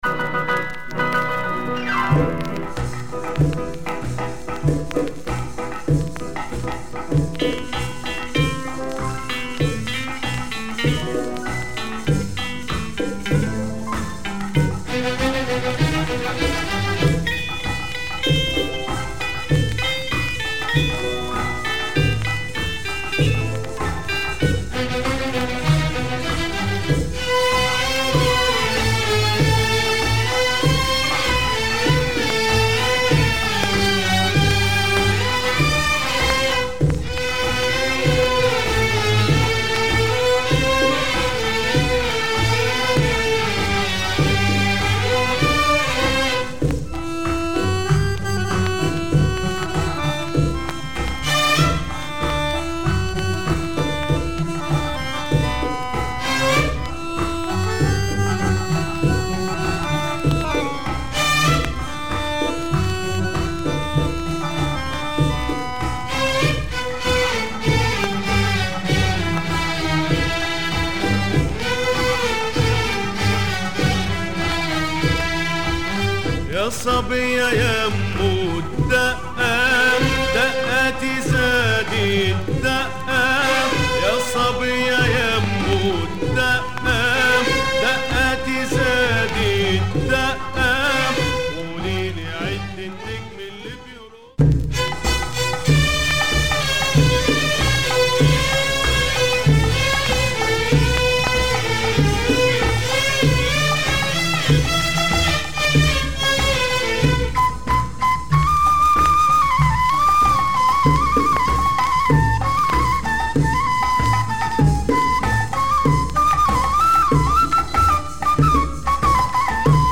Late 60's session
guitar
organ